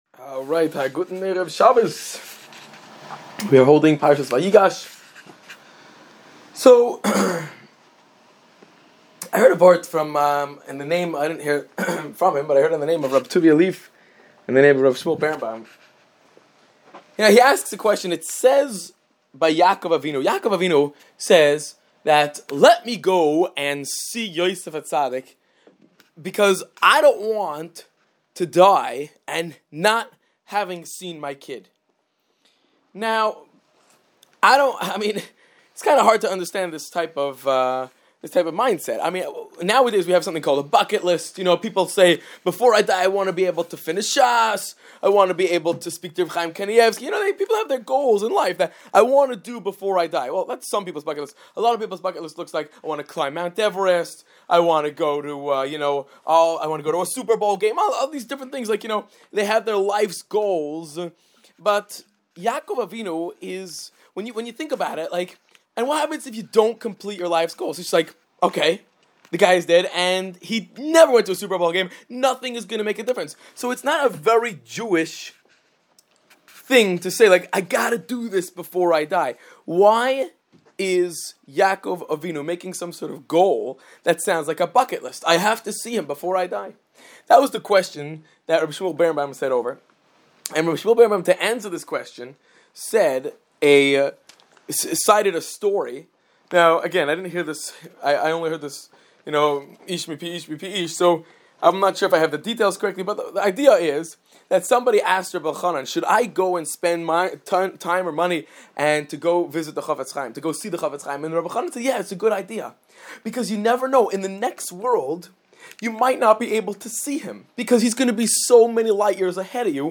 Shabbos speech Parshas Vayigash 5780